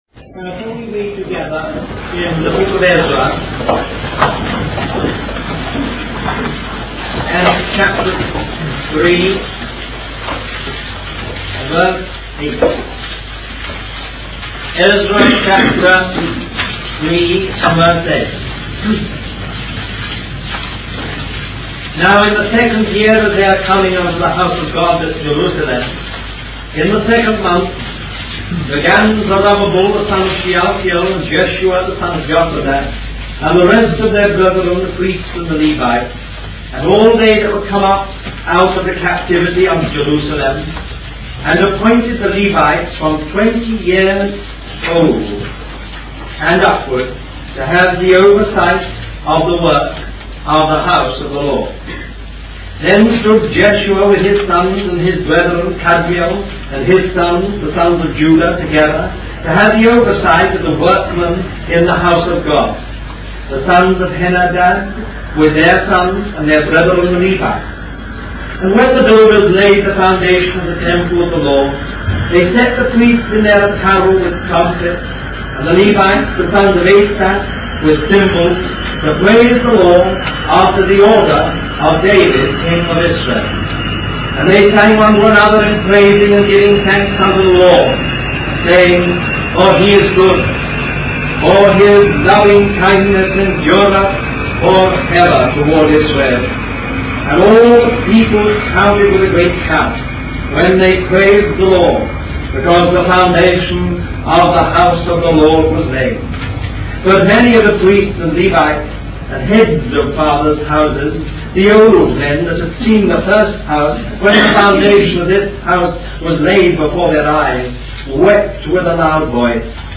Spoken in England January 1, 1965